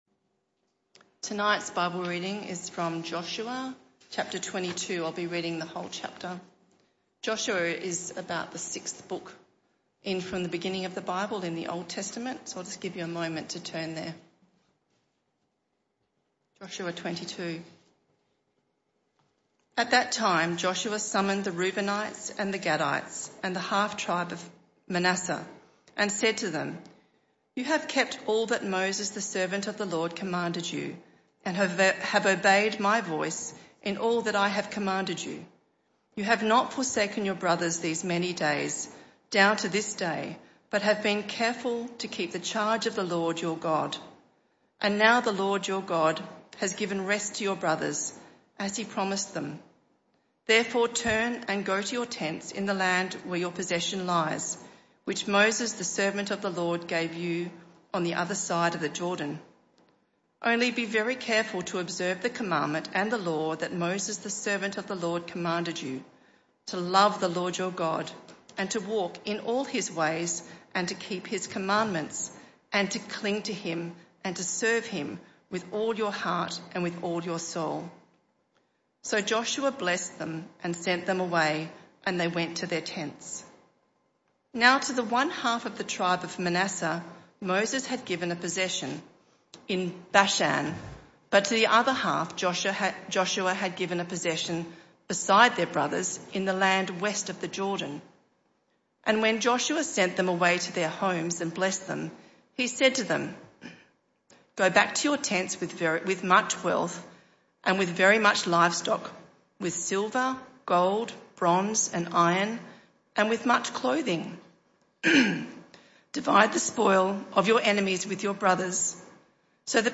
This talk was part of the PM Service series entitled The People Of God’s Promise.